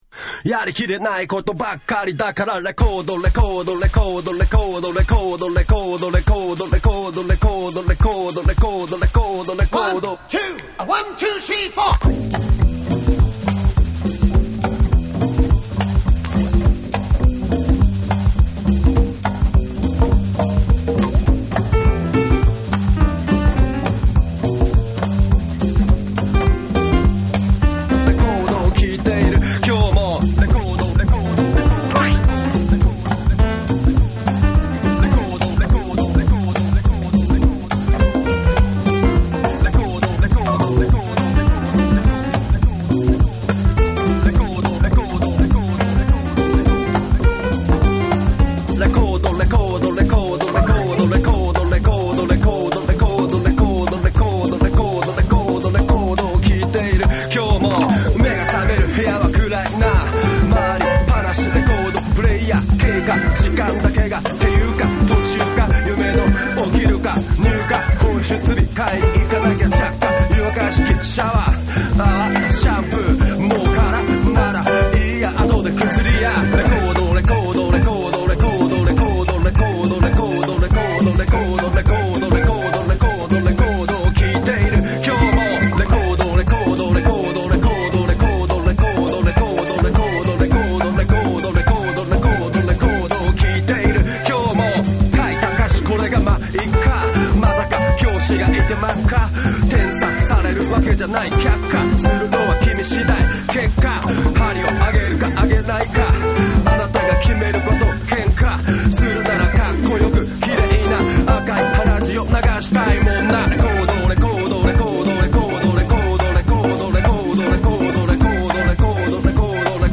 JAPANESE / HIP HOP